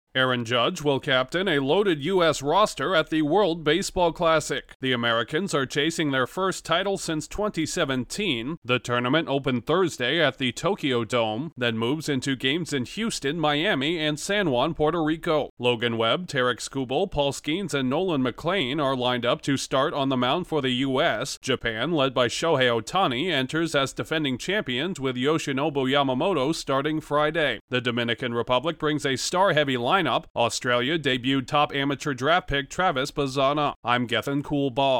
Team USA is hopeful to reclaim its spot as World Baseball Classic champions. Correspondent